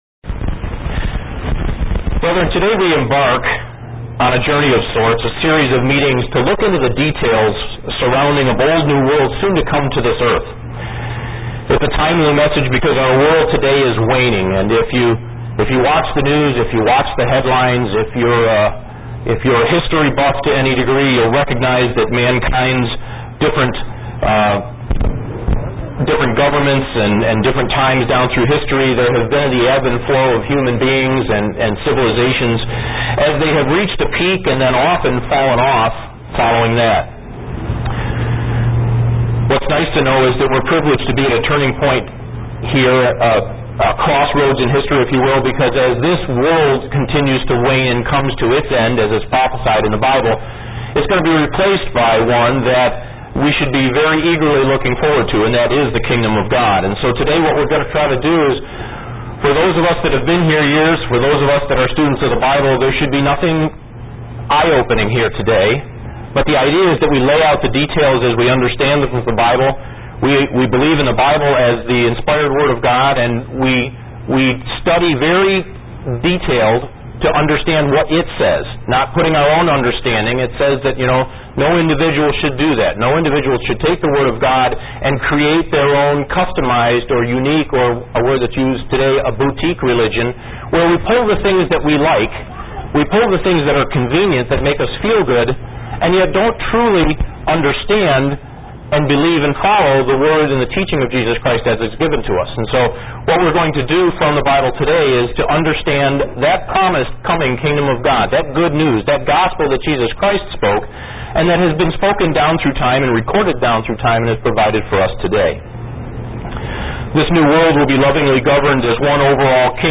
Given in Buffalo, NY
UCG Sermon Studying the bible?